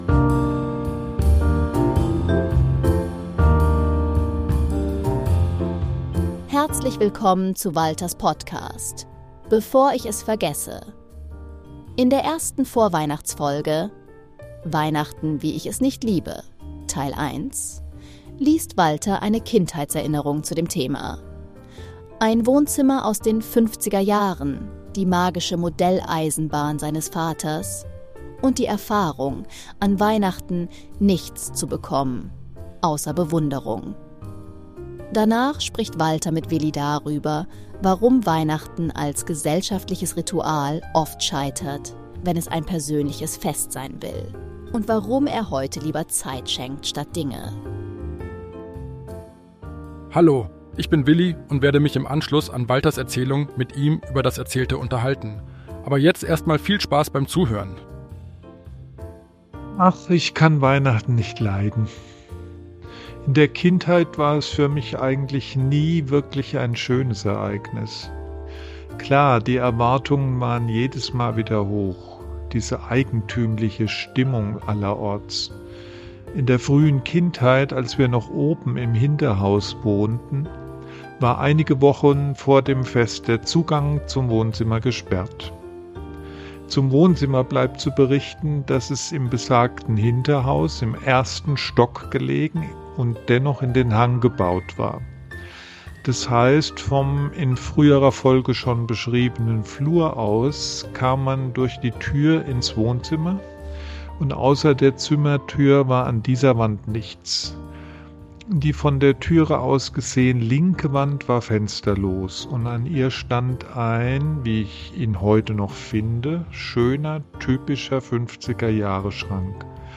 Beschreibung vor 4 Tagen • Titel: Weihnachten, wie ich es nicht liebe – Teil 1 • Untertitel: Modelleisenbahn, Magie und enttäuschte Erwartungen • Beschreibung: In dieser Weihnachtsfolge lese ich eine Kindheitserinnerung: Ein Wohnzimmer wie aus den 50ern, die magische Modelleisenbahn meines Vaters – und die Erfahrung, an Weihnachten „nichts“ zu bekommen, außer Bewunderung. Danach spreche ich darüber, warum Weihnachten als gesellschaftliches Ritual oft scheitert, wenn es ein persönliches Fest sein will, und warum ich heute lieber Zeit schenke statt Dinge.